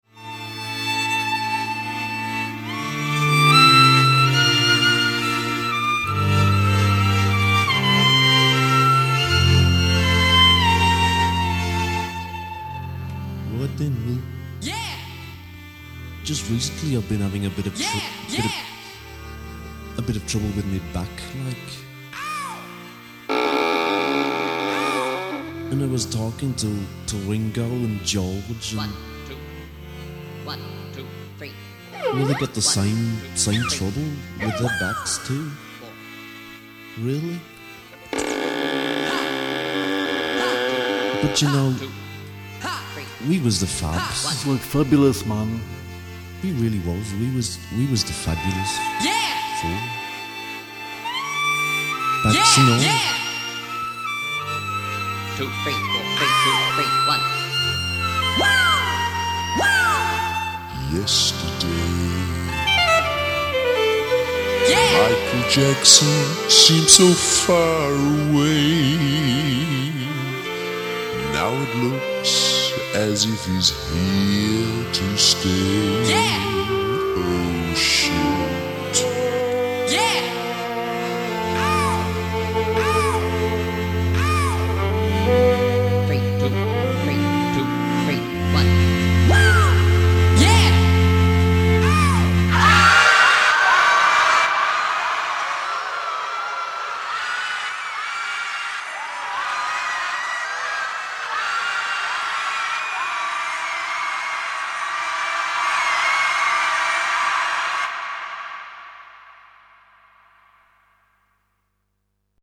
h_radio_virtual_violin.mp3